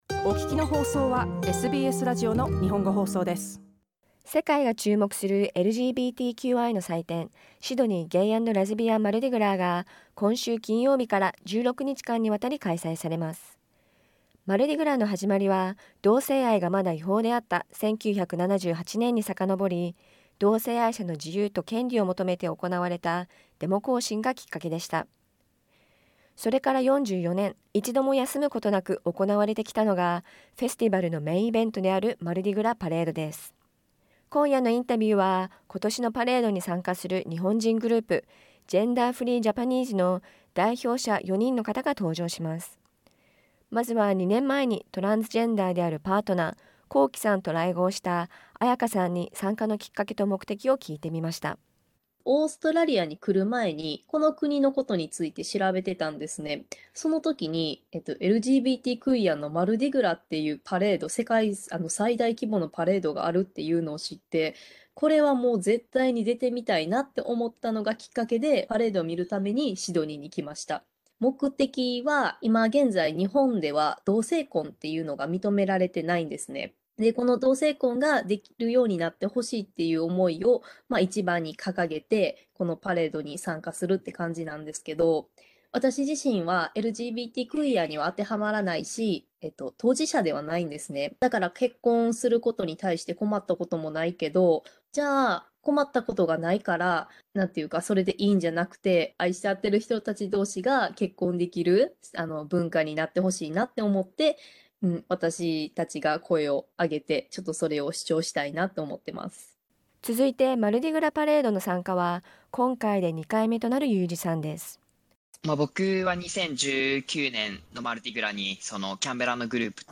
japanese_interview_mardi_gras_parade_gender_free_japanese_15_fb_2.mp3